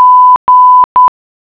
Nota.- Para escuchar el sonido, hacer clic en la clave morse correspondiente (el sonido se escuchará a una velocidad de 10 palabras/minuto).